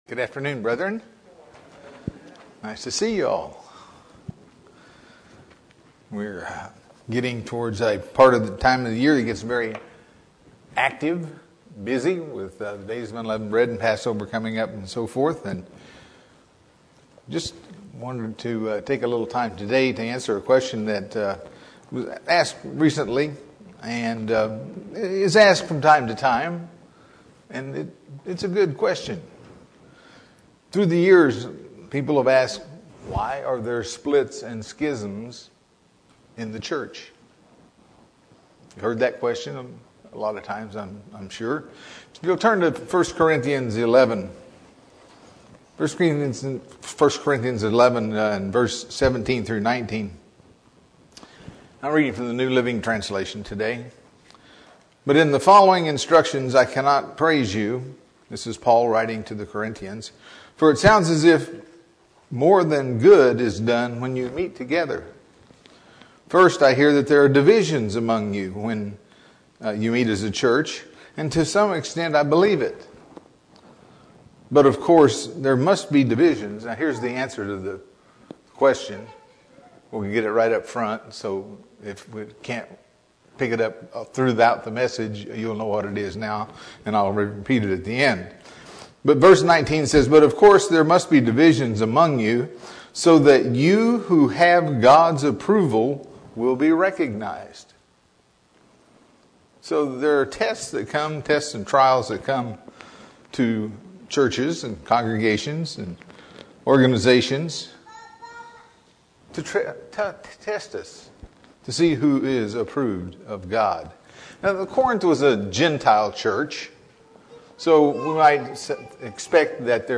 Sermons
Given in San Diego, CA